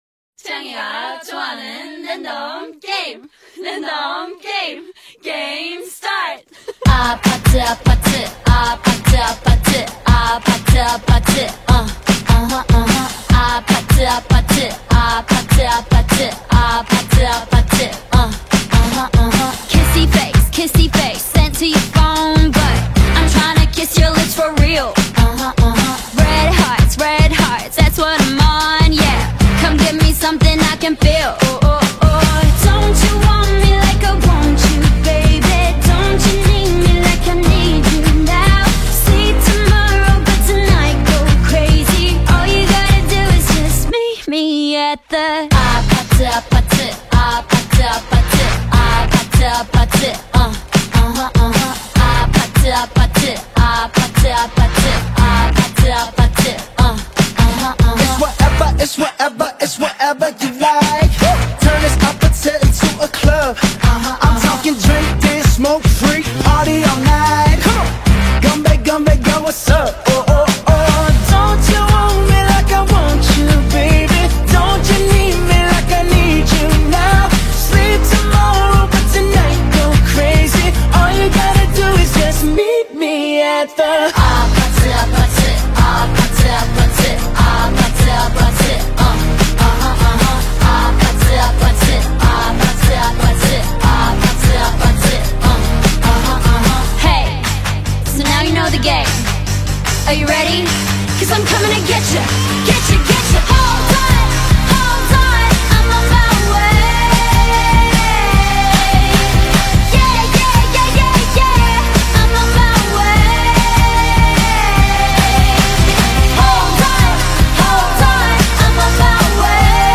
With a blend of soulful vocals and catchy melodies
Groove to the infectious rhythm